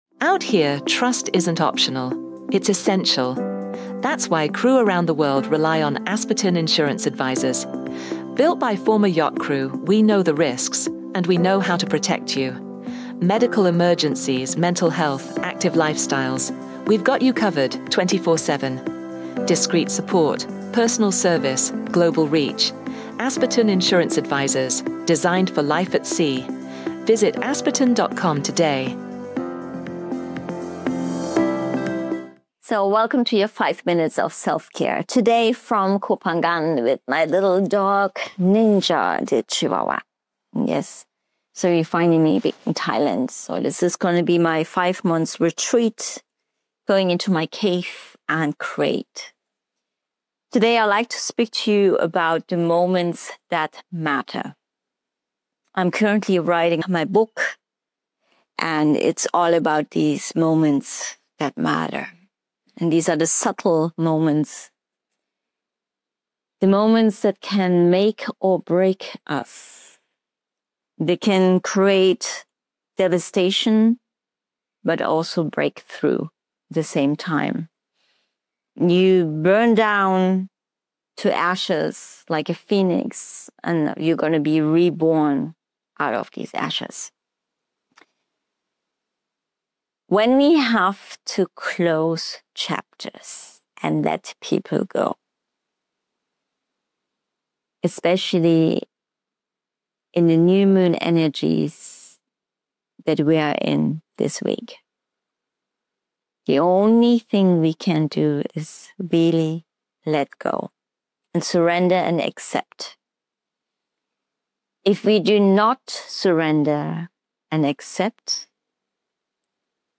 recorded in rural Thailand during monsoon season